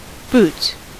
Ääntäminen
IPA : /buːts/